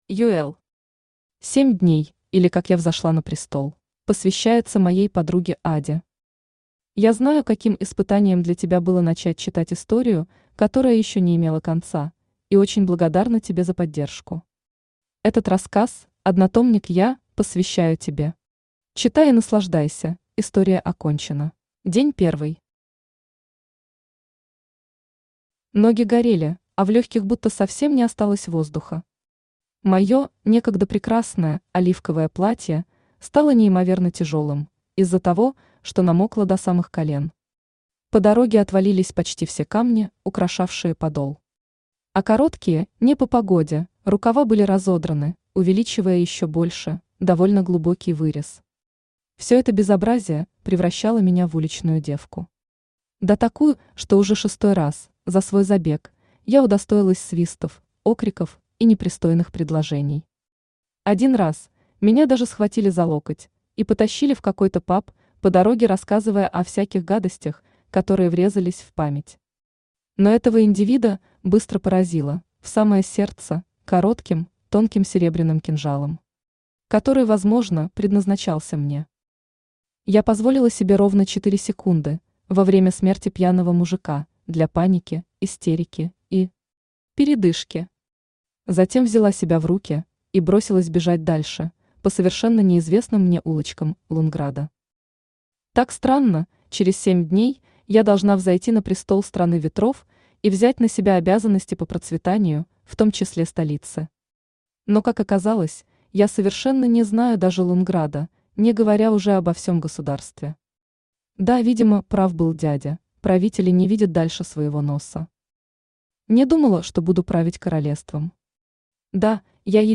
Aудиокнига 7 Дней, или Как я взошла на престол Автор ЮЭл Читает аудиокнигу Авточтец ЛитРес.